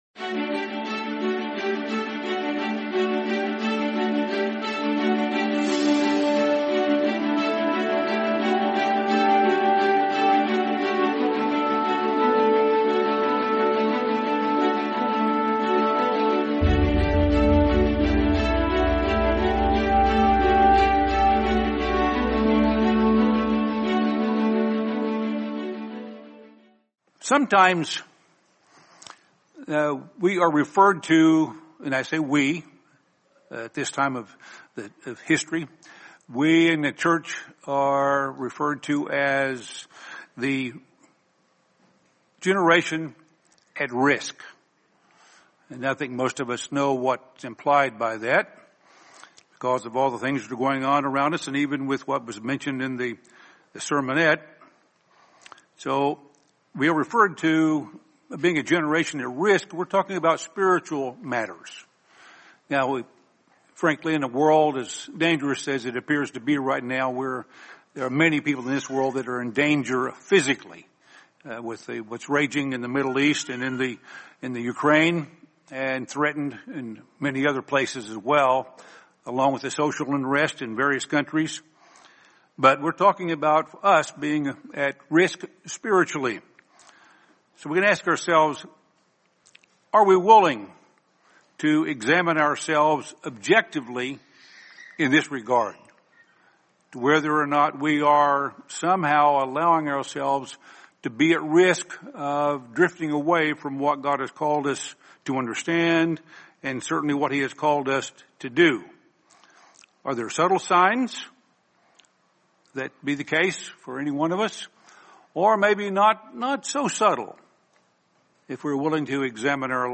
Don't Be Part of the At-Risk Generation | Sermon | LCG Members